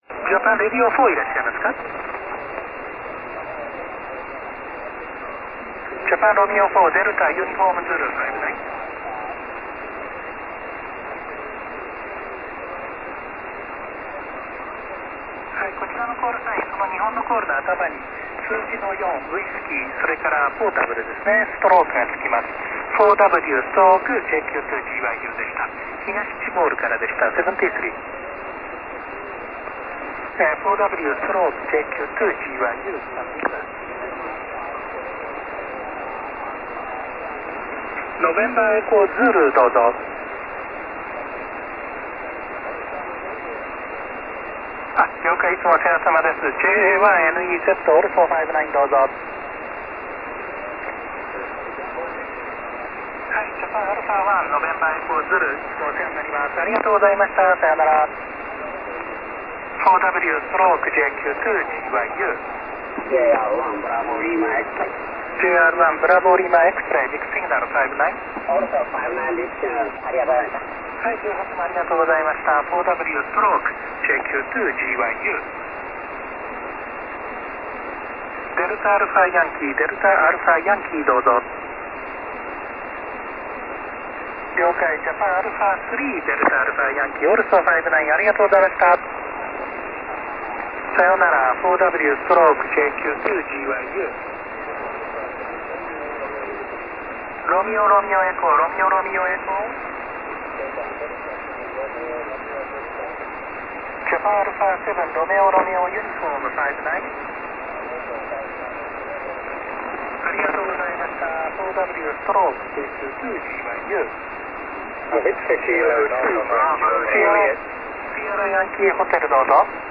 21.202MHz SSB